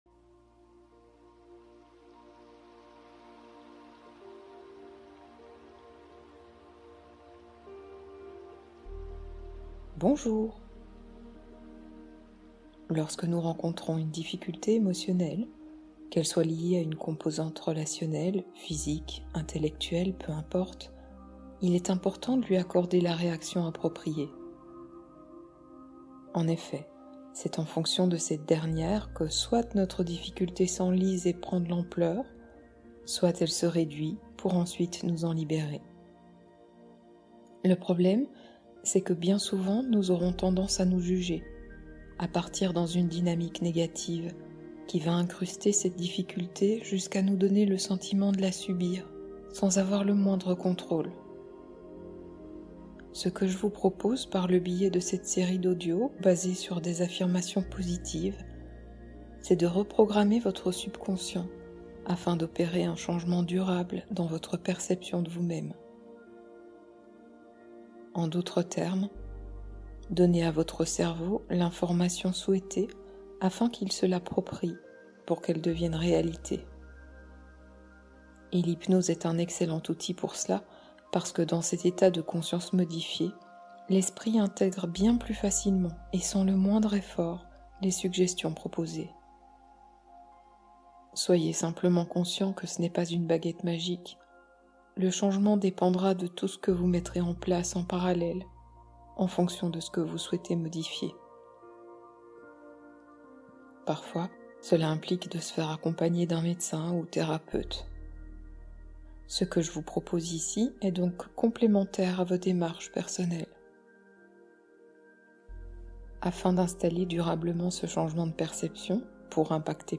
Sommeil lumineux : hypnose sécurisante et réparatrice